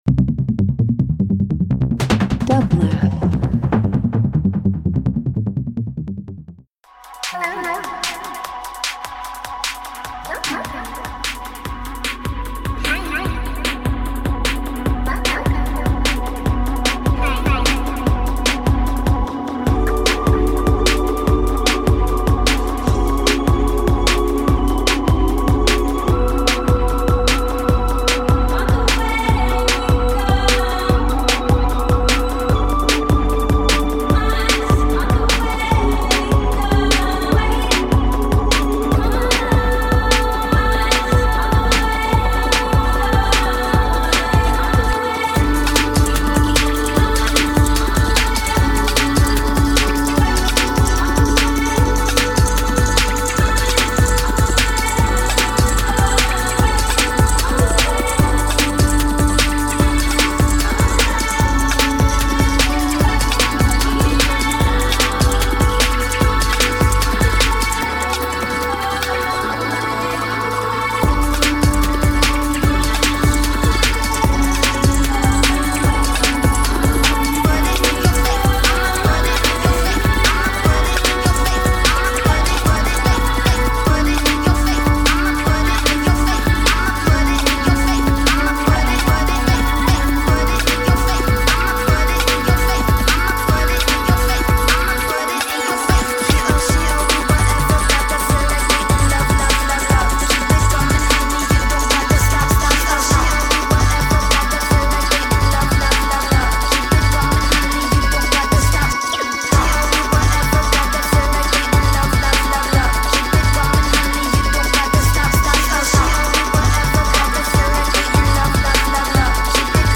Dance House Indie Techno